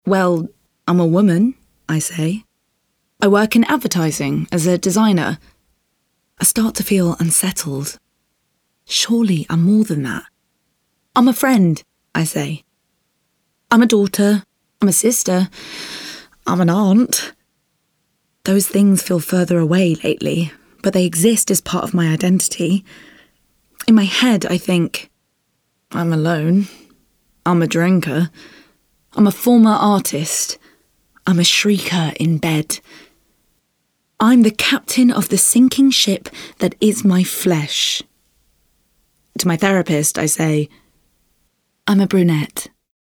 Playing age: Teens - 20s, 20 - 30sNative Accent: RPOther Accents: American, Australian, Liverpool, London, Manchester, Neutral, Northern, RP, West Country
• Native Accent: British RP